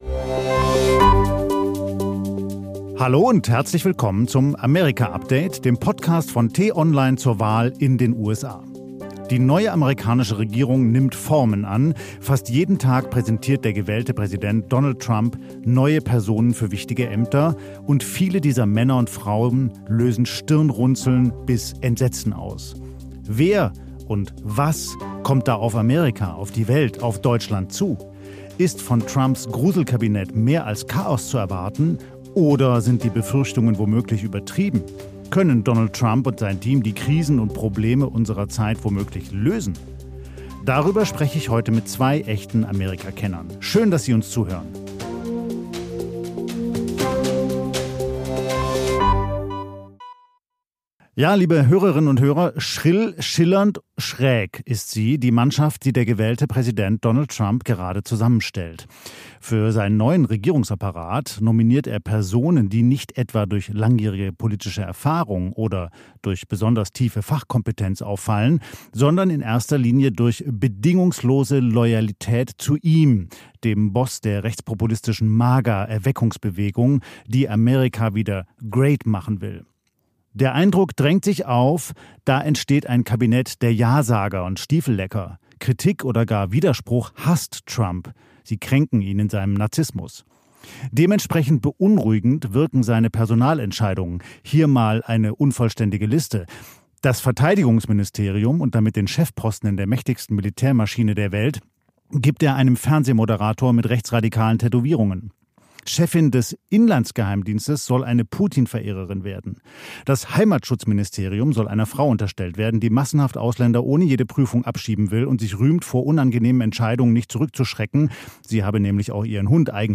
Den „Tagesanbruch“-Podcast gibt es immer montags bis samstags gegen 6 Uhr zum Start in den Tag – am Wochenende mit einer tiefgründigeren Diskussion.